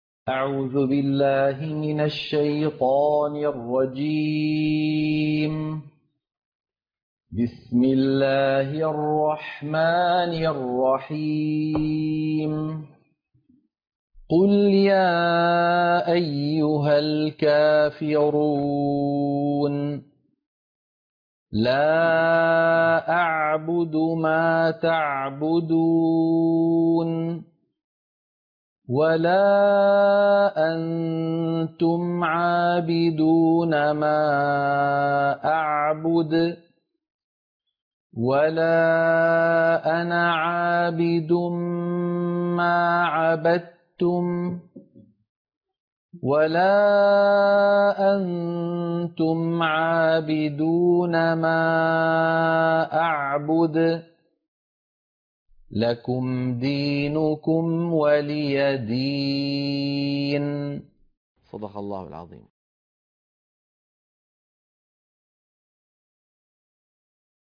عنوان المادة سورة الكافرون - القراءة المنهجية